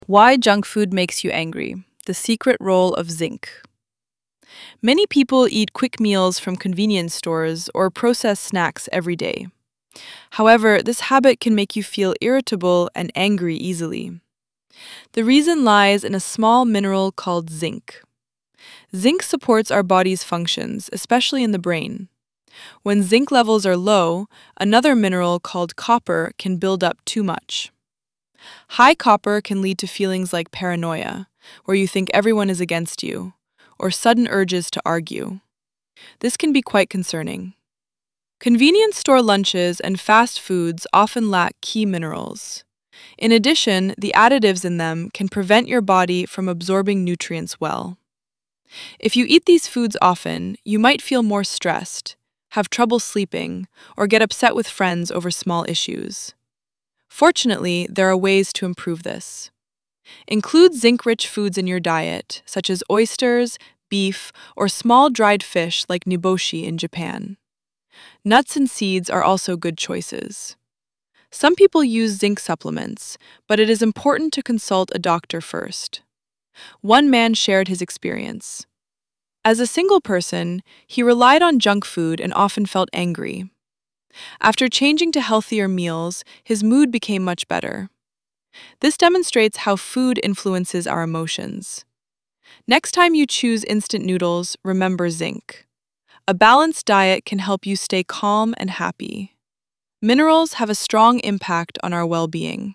＜音読用音声＞